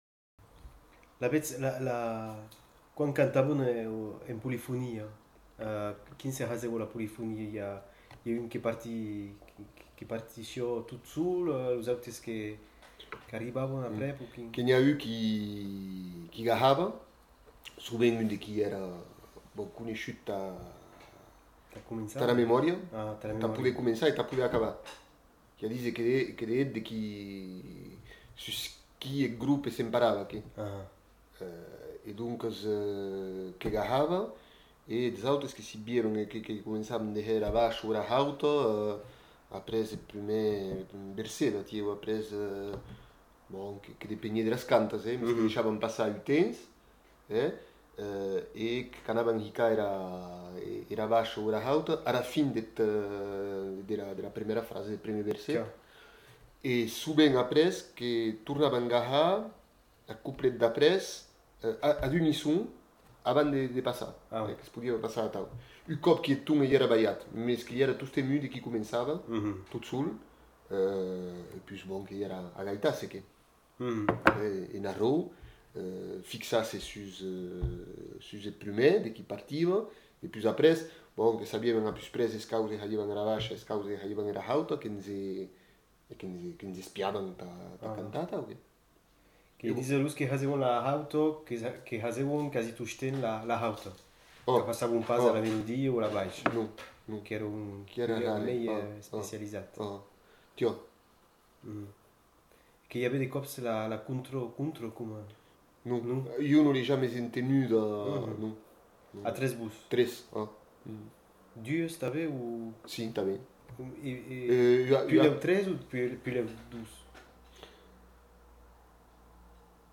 Aire culturelle : Bigorre
Lieu : Ayzac-Ost
Genre : témoignage thématique